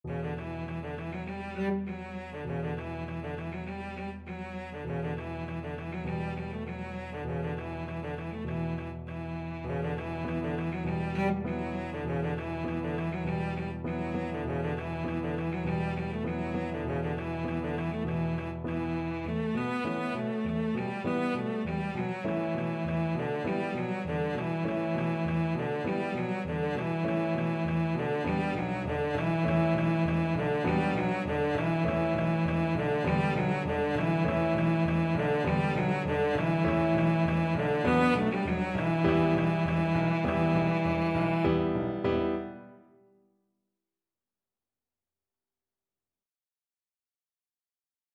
Cello version
Traditional Music of unknown author.
2/4 (View more 2/4 Music)
World (View more World Cello Music)